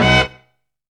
SLAM HIT.wav